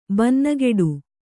♪ bannageḍu